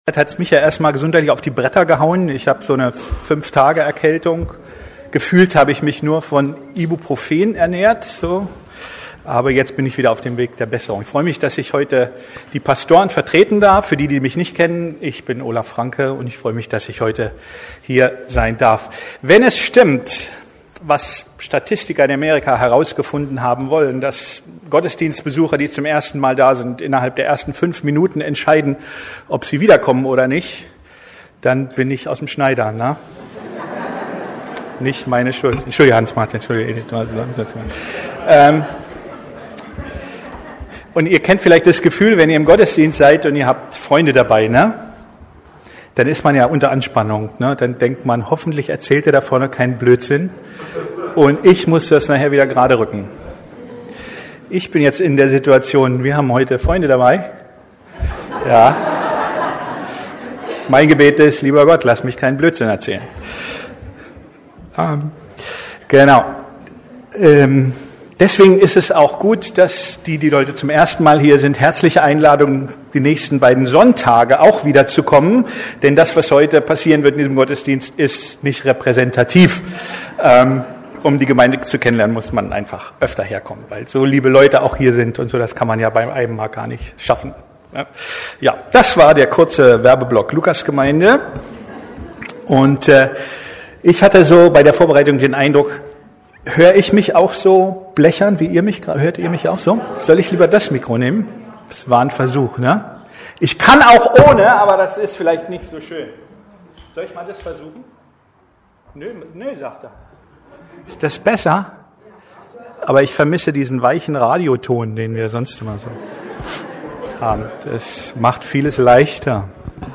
Gebet. Warum? Wie und Was? ~ Predigten der LUKAS GEMEINDE Podcast